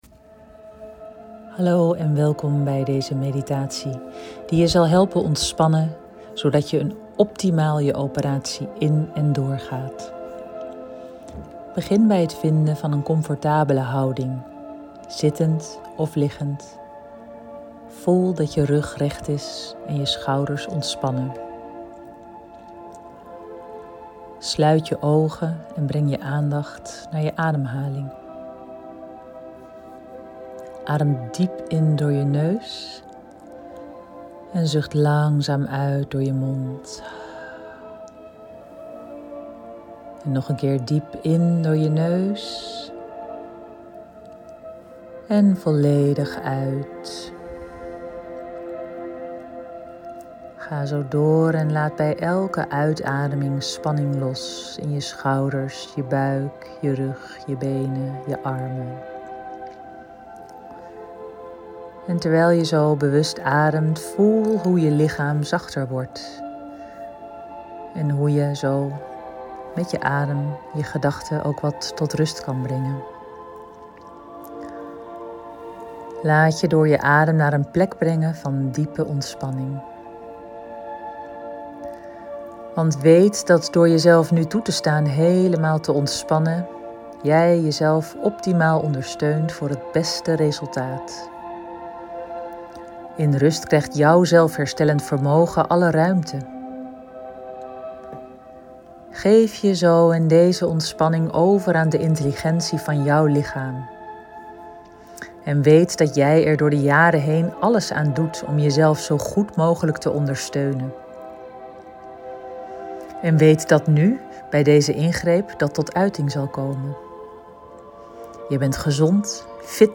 Preoperatieve ontspanningsmediatie